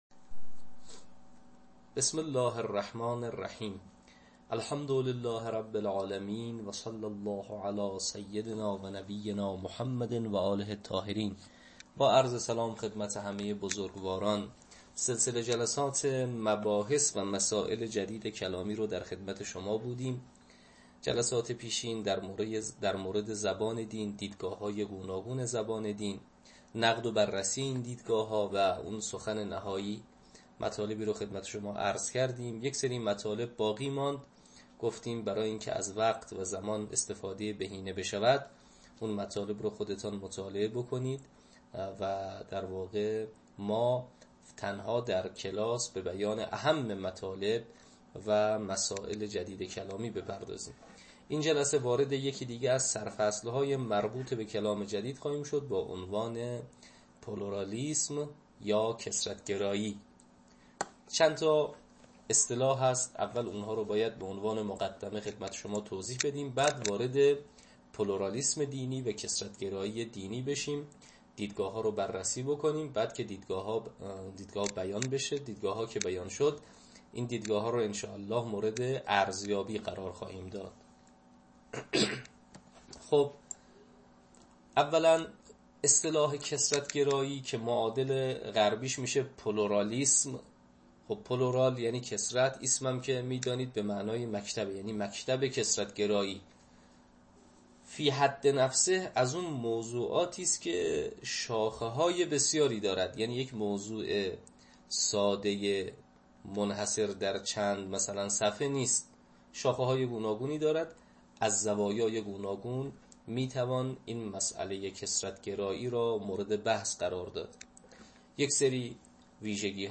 تدریس کلام جدید